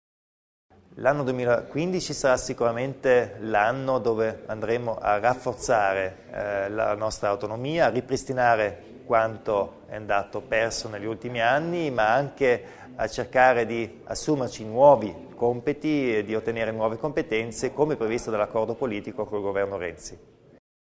La riforma del sostegno all'economia, il riordino del settore energetico locale, le misure anti-inquinamento lungo l'asse del Brennero, le opere infrastrutturali approvate e avviate nel 2014: sono alcuni dei punti affrontati oggi (29 dicembre) a Bolzano dal presidente della Provincia Arno Kompatscher nella conferenza stampa di fine anno.